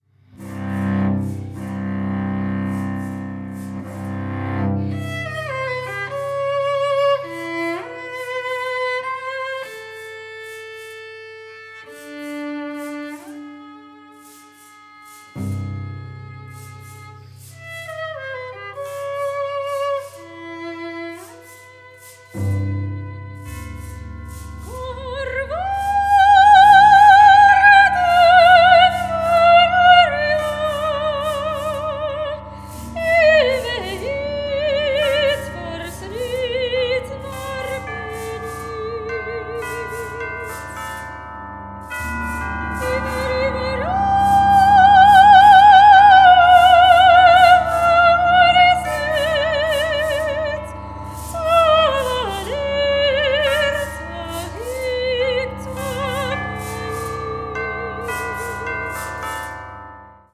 cello, soprano
percussion